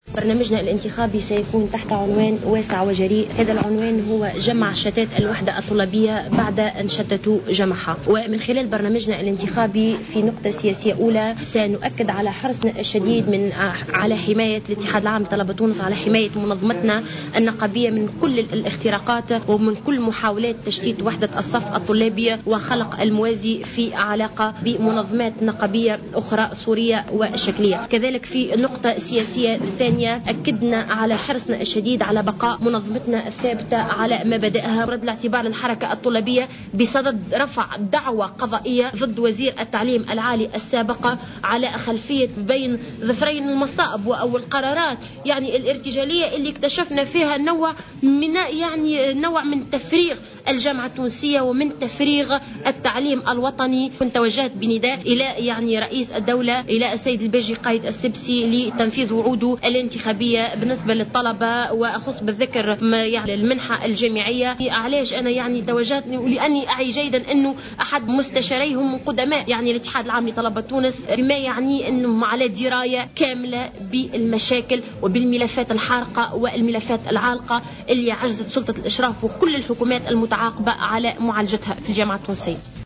خلال ندوة صحفية عقدها الاتحاد في تونس العاصمة اليوم